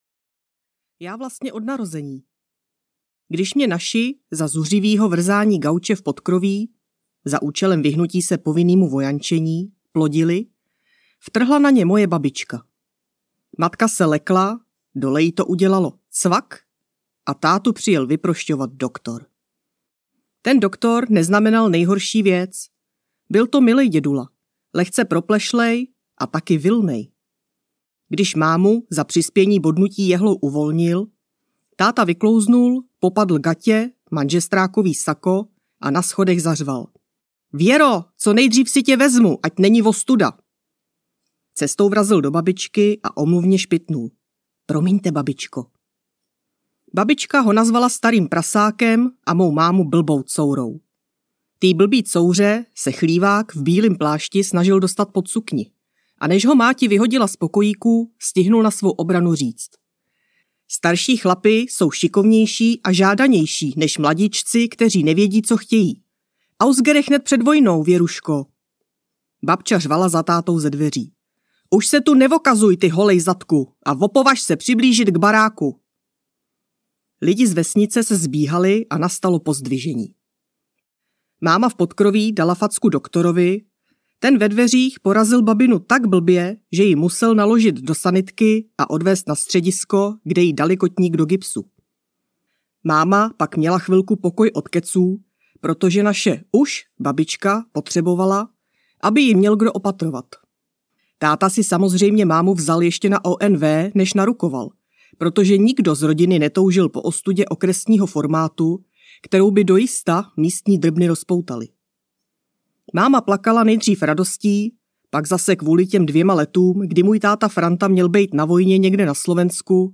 Sex, prachy a potíže audiokniha
Ukázka z knihy